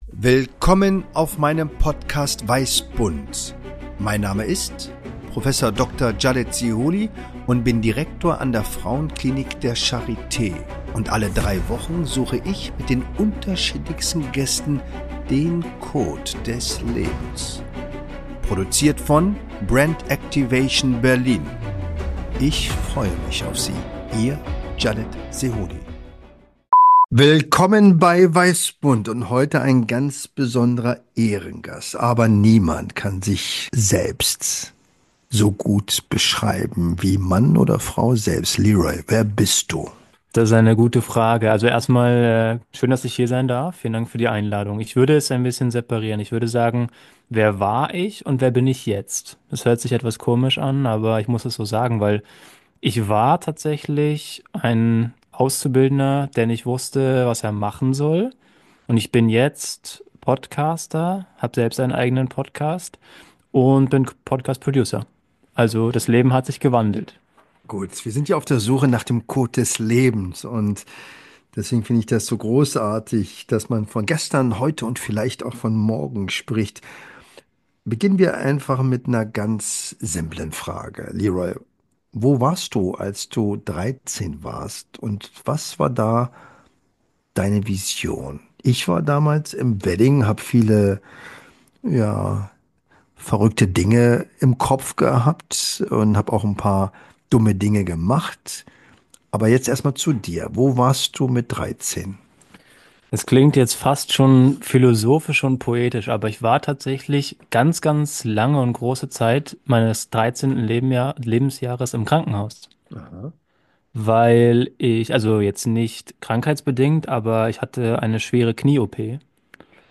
Spontan, intuitiv, ohne Skript, Improvisation pur!